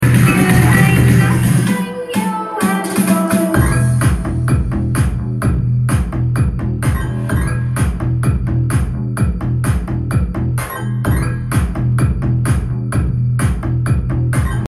Speaker bass check dual 5 inches sound effects free download
Speaker bass check-dual 5 inches speaker woofer shocked sound